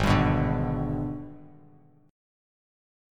G#mM7b5 chord